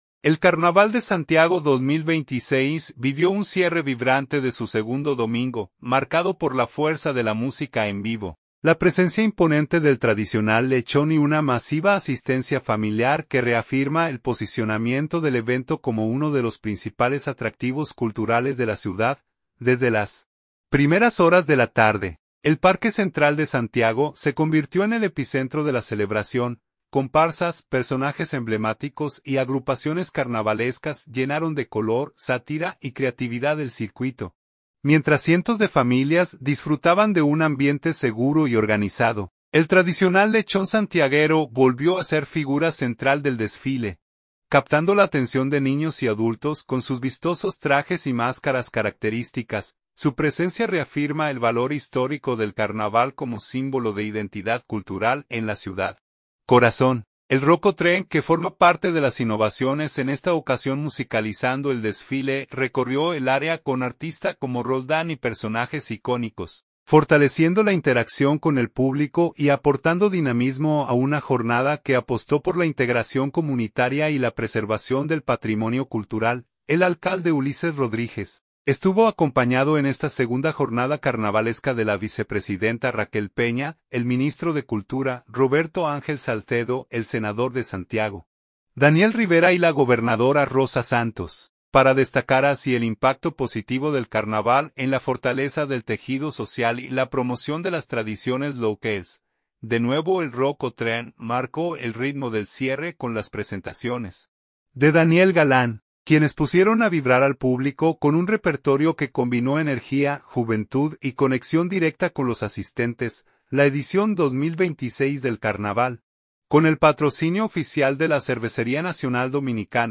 El Carnaval de Santiago 2026 vivió un cierre vibrante de su segundo domingo, marcado por la fuerza de la música en vivo, la presencia imponente del tradicional lechón y una masiva asistencia familiar que reafirma el posicionamiento del evento como uno de los principales atractivos culturales de la ciudad.
Desde las primeras horas de la tarde, el Parque Central de Santiago se convirtió en el epicentro de la celebración.